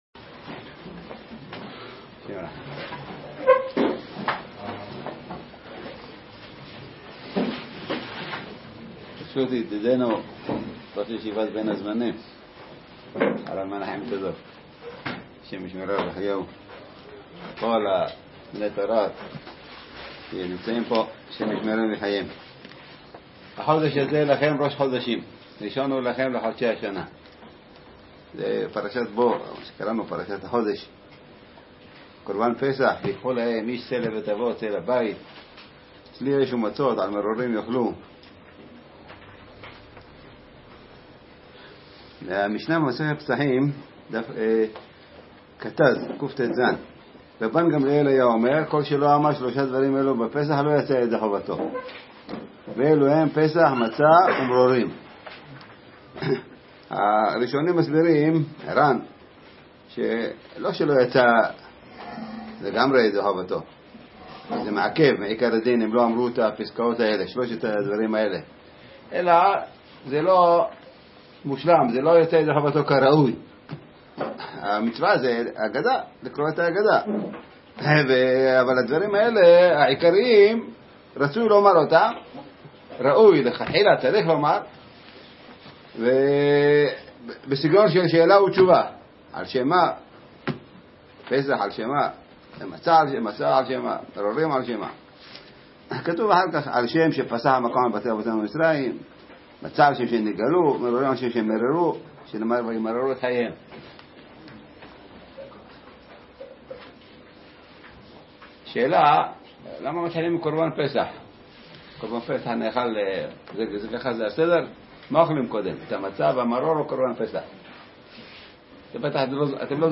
חדש! שיעור תורה ודברי חיזוק לבחורי הישיבות הקדושות - בני ברק ת"ו